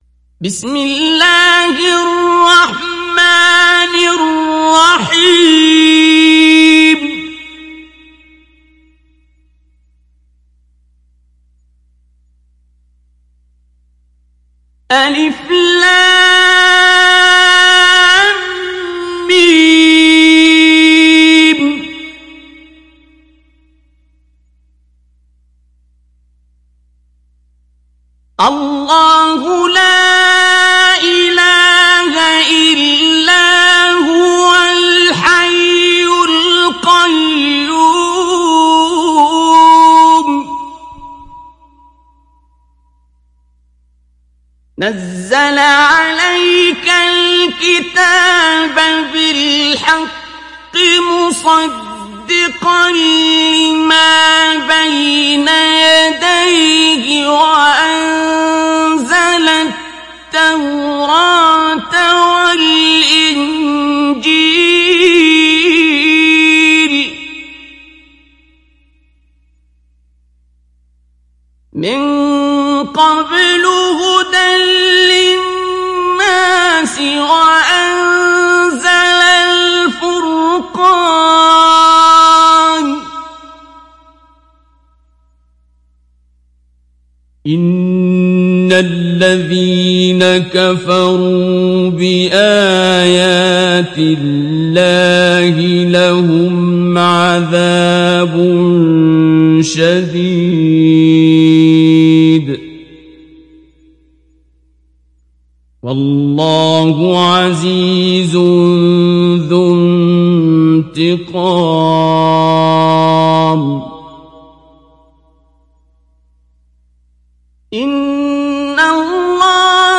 Télécharger Sourate Al Imran Abdul Basit Abd Alsamad Mujawwad